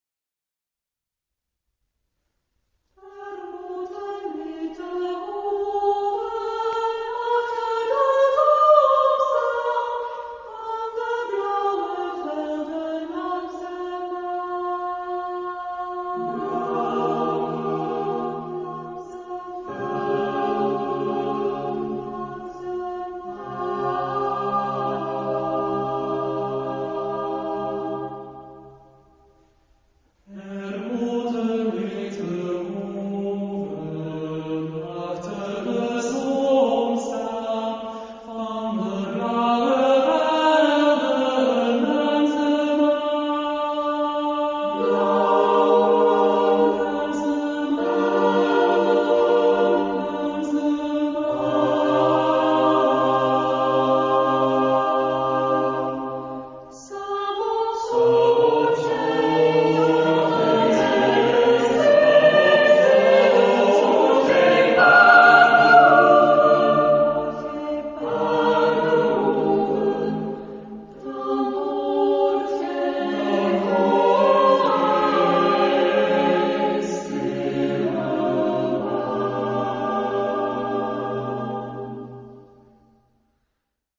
Genre-Style-Form: Secular ; Poem
Mood of the piece: calm
Type of Choir: SATB  (4 mixed voices )